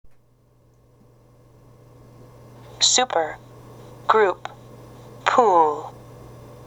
[ウー] super, group, pool